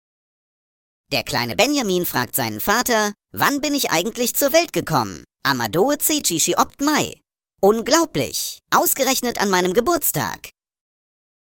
Vorgetragen von unseren attraktiven SchauspielerInnen.
Comedy , Unterhaltung , Kunst & Unterhaltung